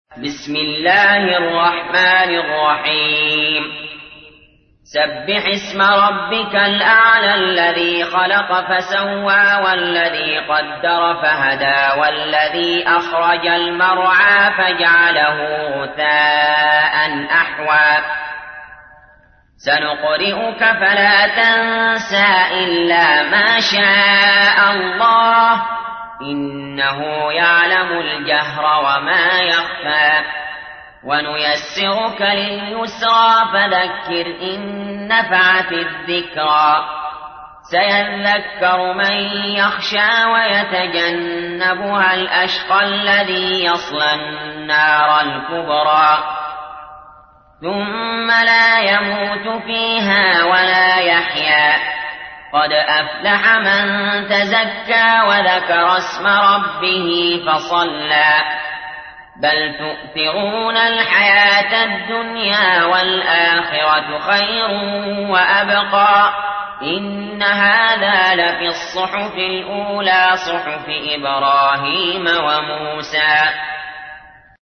تحميل : 87. سورة الأعلى / القارئ علي جابر / القرآن الكريم / موقع يا حسين